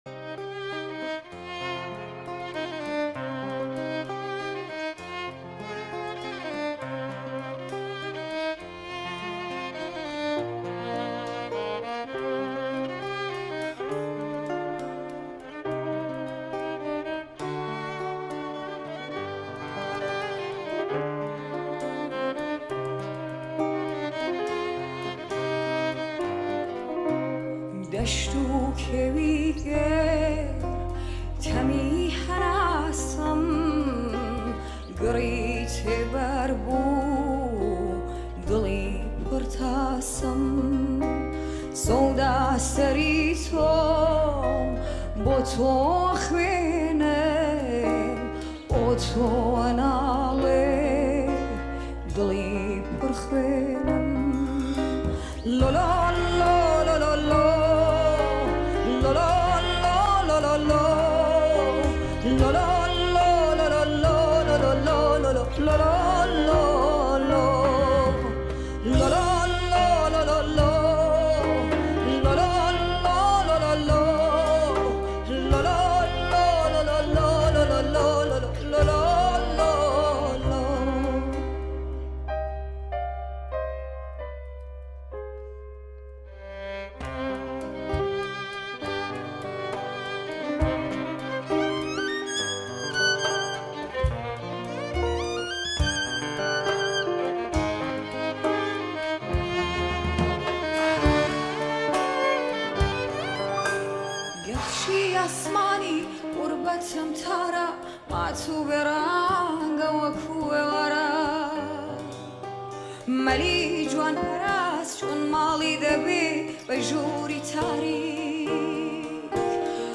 ترانه کوردی گورانی کوردی